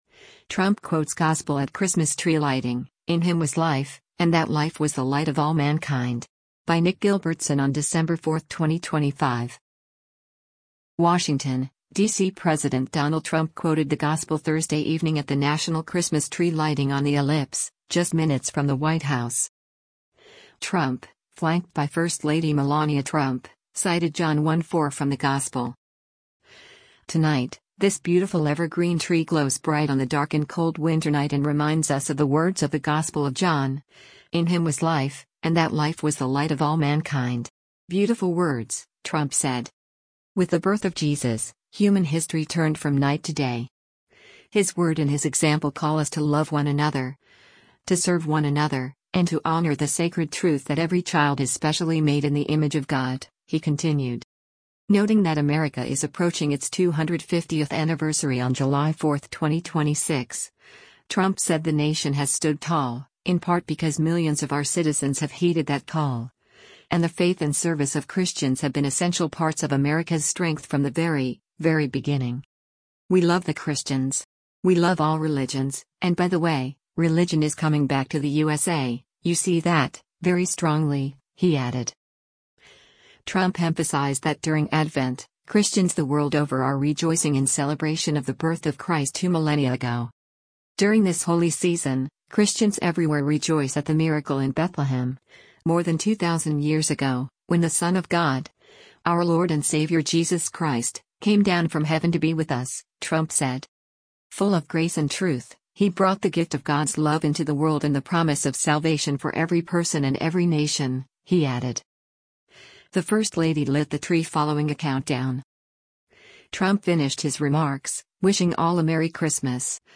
WASHINGTON, DC–President Donald Trump quoted the Gospel Thursday evening at the National Christmas Tree lighting on the Ellipse, just minutes from the White House.